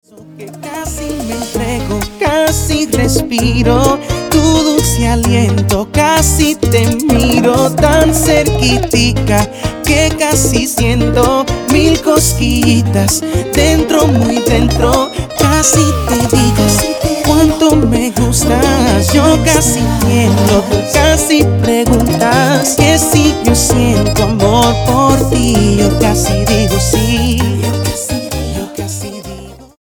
Bachata Charts - Februar 2011